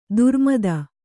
♪ durmada